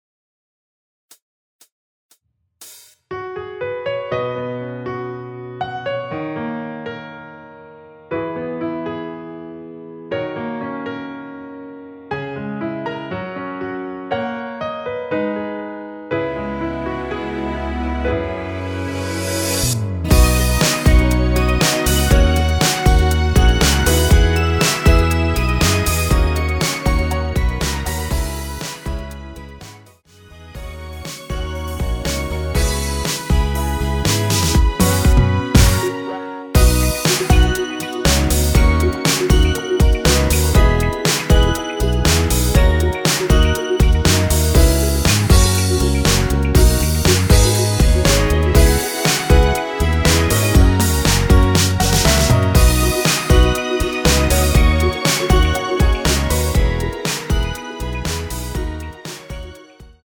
전주 없이 시작 하는곡이라 카운트 4박 넣어 놓았습니다.(미리듣기 참조)
앞부분30초, 뒷부분30초씩 편집해서 올려 드리고 있습니다.